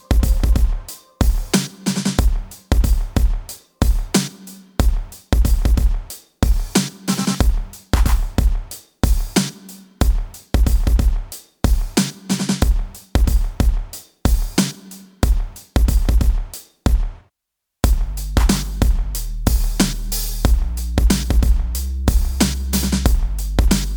Minus Guitars Except Rhythms Pop (2010s) 3:46 Buy £1.50